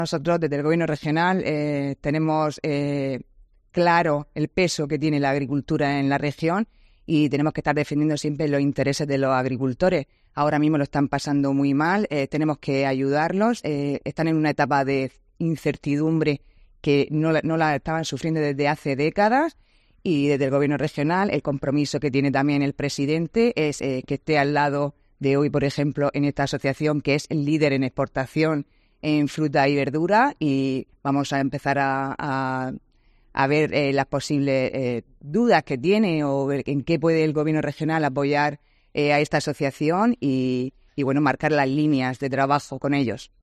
Sara Rubira, consejera de Agricultura